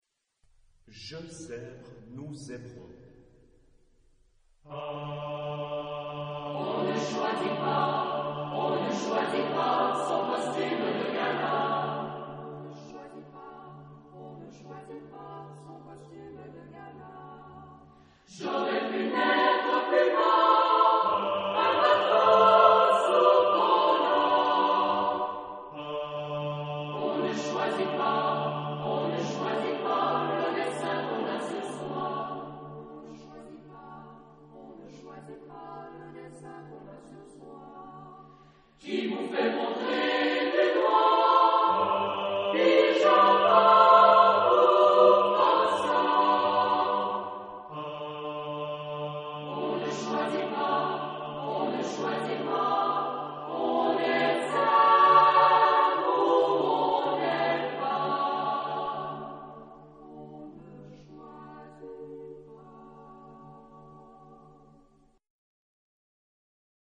Genre-Style-Form: Choral suite ; Partsong ; Poem ; Secular
Mood of the piece: freely ; humorous
Type of Choir: SATB  (4 mixed voices )
Tonality: D major